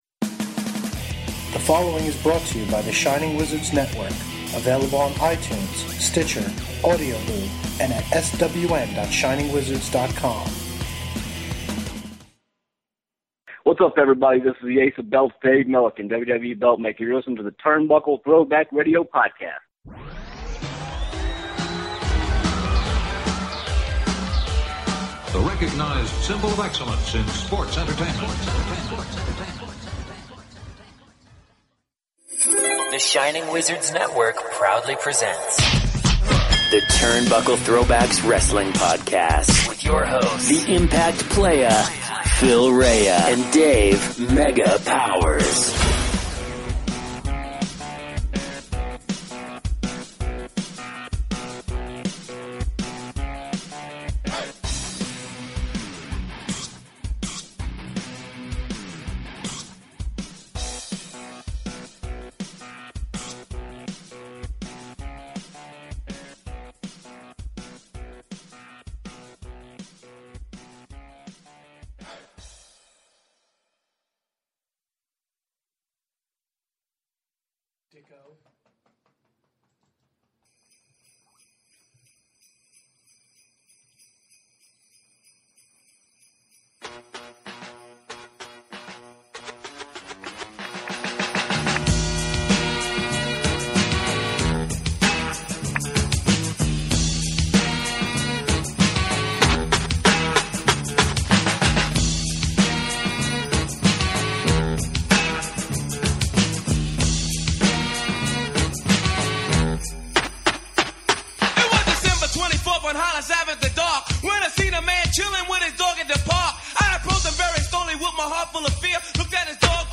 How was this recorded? It’s our first annual Christmas spectacular which we broadcasted LIVE on the RANT network.